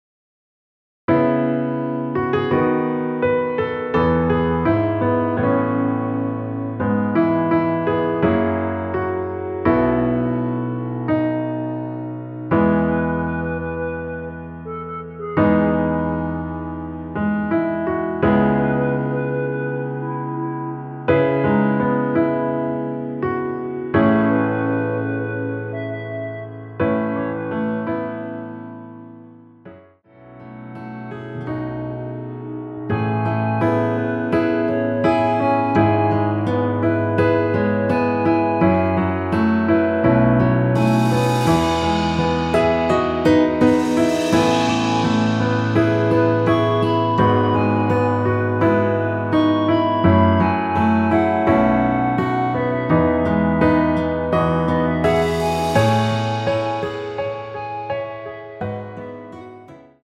원키에서(+5)올린 멜로디 포함된 MR입니다.
앞부분30초, 뒷부분30초씩 편집해서 올려 드리고 있습니다.
중간에 음이 끈어지고 다시 나오는 이유는